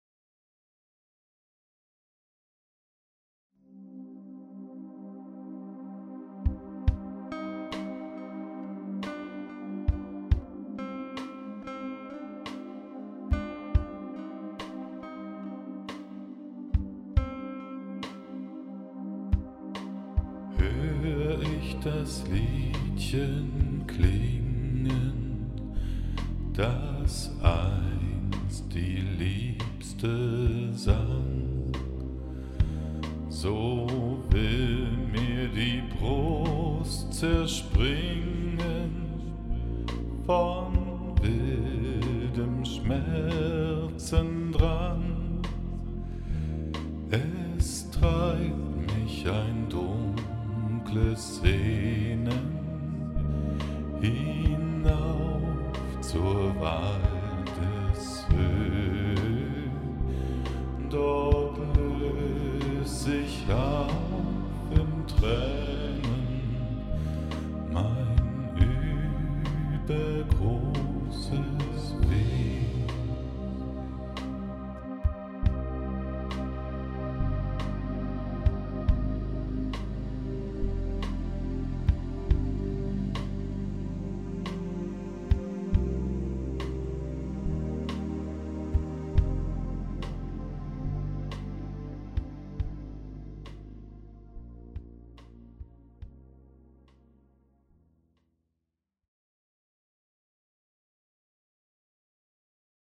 hallo in die runde, es handelt sich hier um ein lied des romantischen komponisten robert schumann, das ich gern mal anders singen und neu arrangieren wollte.. getan. das ist das ergebnis bis hierher. kann man so machen oder eher nich..?? wie kommts an im ohr..?? wie der (sparsame) mix ?? das...
die bearbeitung hört jetzt noch nach dem gesang auf.